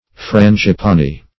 Frangipani \Fran`gi*pan"i\, Frangipanni \Fran`gi*pan"ni\, n.